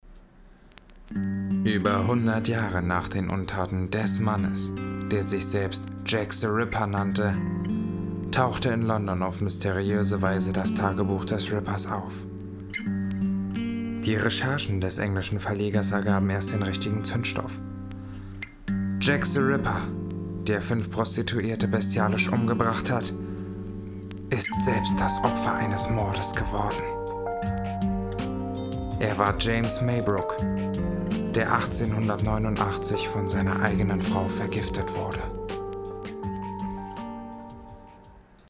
Sprechprobe: eLearning (Muttersprache):
german voice over artist